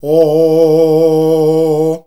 OOOOH   A#.wav